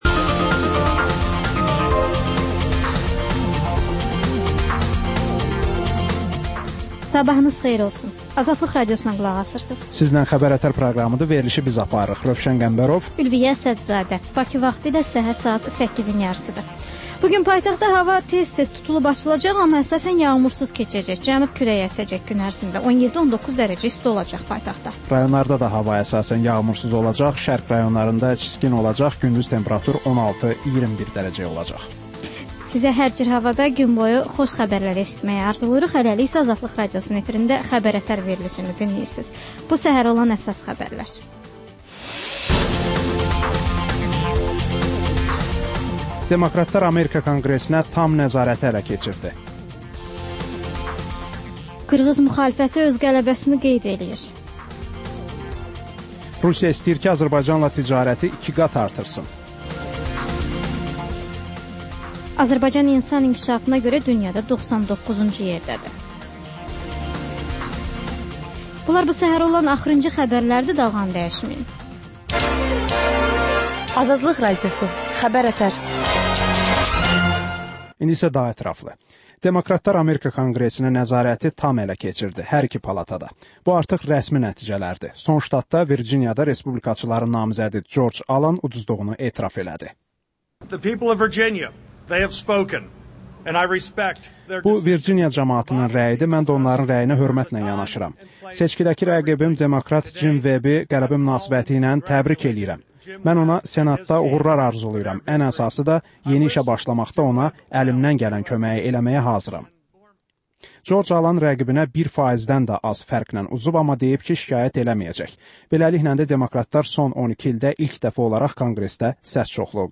Səhər-səhər, Xəbər-ətərş Xəbərlər, reportajlar, müsahibələr